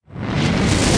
FireballCreate.wav